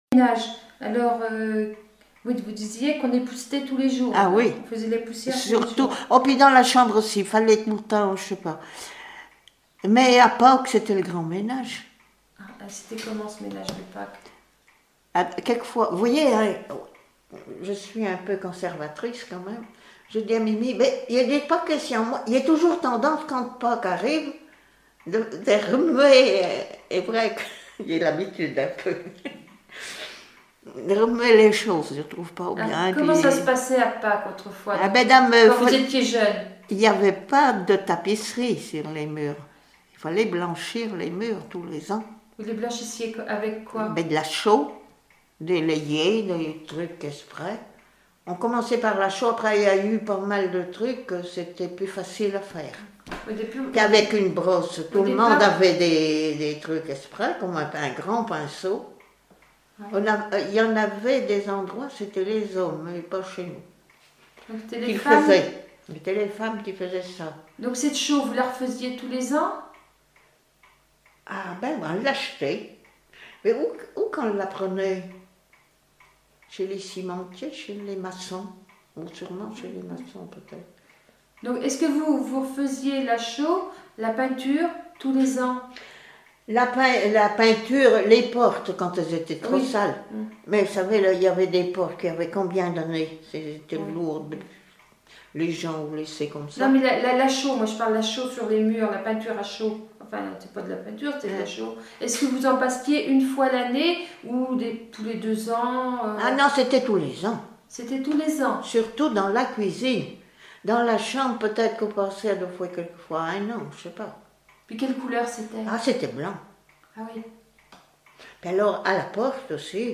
Enquête Arexcpo en Vendée-Pays Sud-Vendée
Catégorie Témoignage